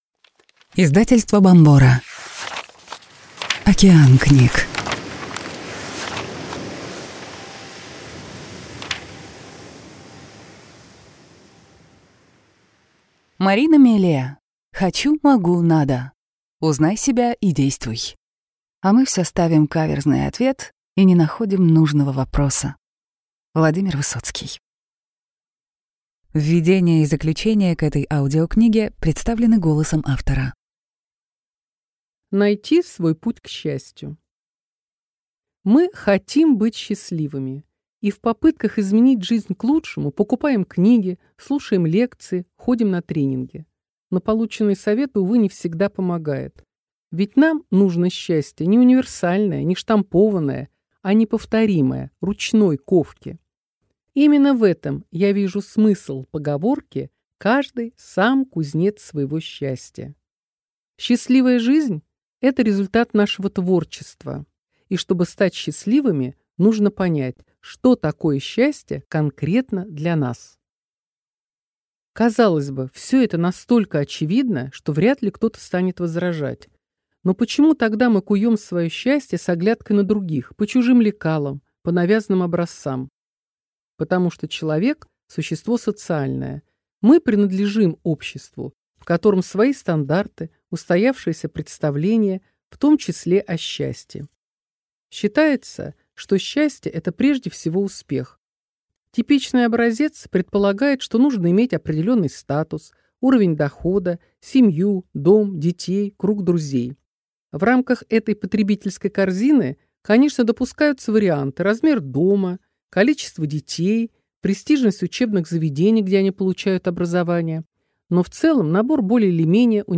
Аудиокнига Хочу – Mогу – Надо. Узнай себя и действуй!